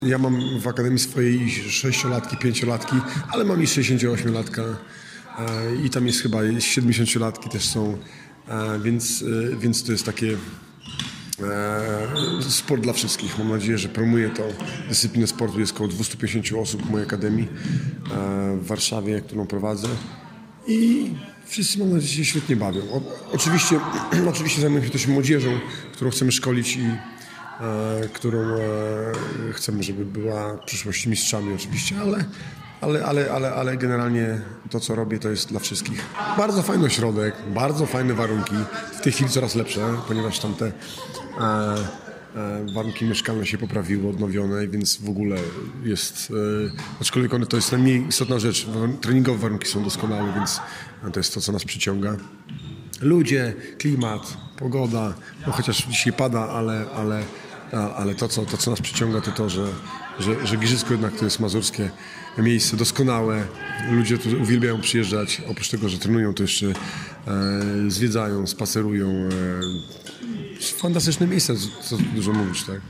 Mówi Michał Łogosz: